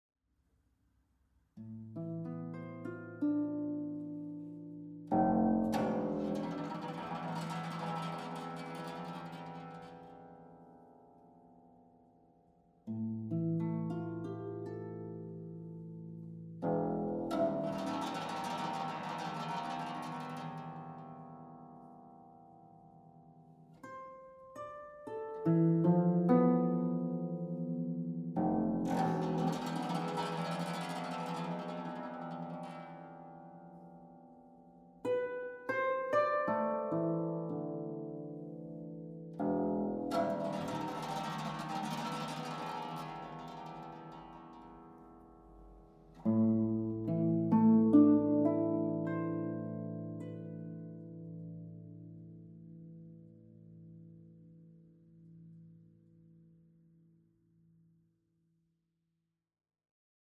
Zeitgenössische Musik für Harfe